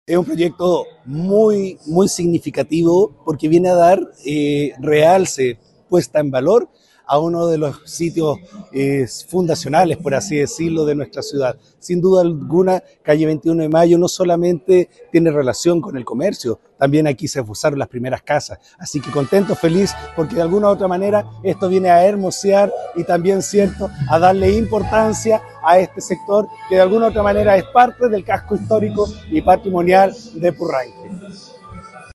El alcalde protocolar de Purranque, René Muñoz, indicó que: Sin duda alguna, Calle 21 de Mayo no solamente tiene relación con el comercio, también aquí se posaron las primeras casas, así que contento, feliz, porque de alguna u otra manera esto viene a hermosear y también, cierto, a darle importancia a este sector «